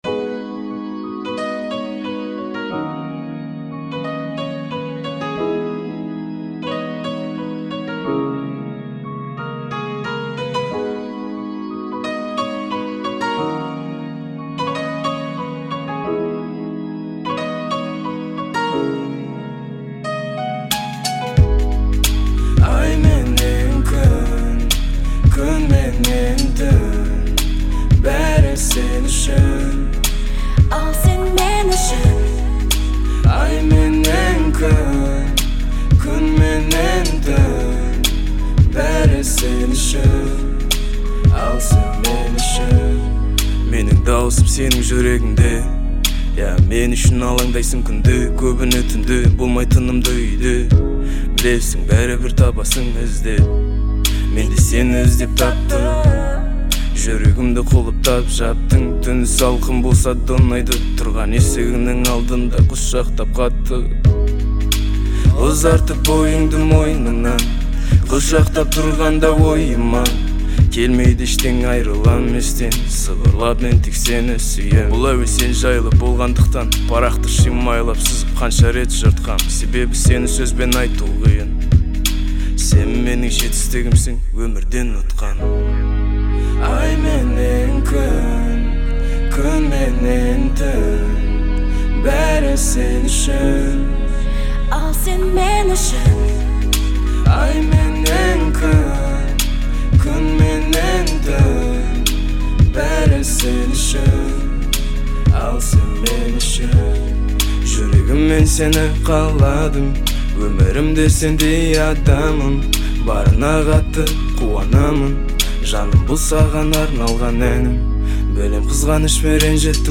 казахской поп-музыки